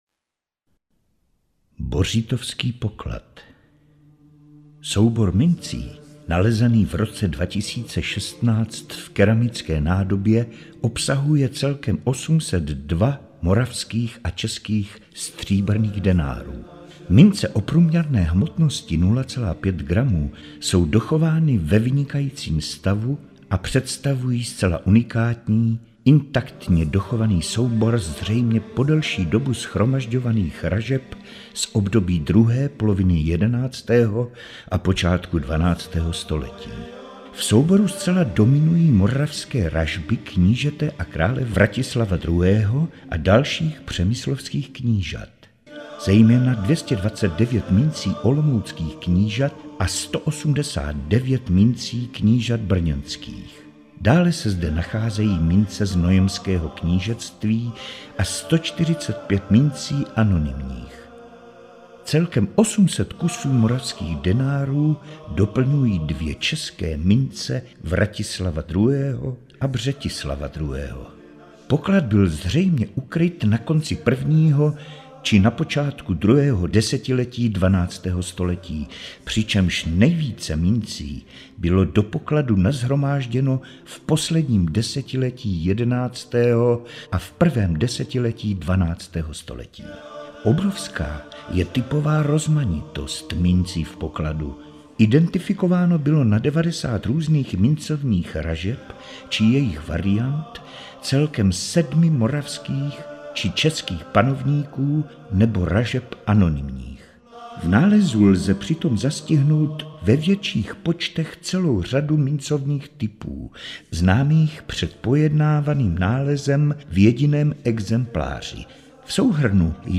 Audioprůvodce